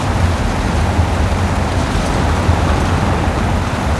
rr3-assets/files/.depot/audio/sfx/tyre_surface/tyres_asphalt_roll.wav
tyres_asphalt_roll.wav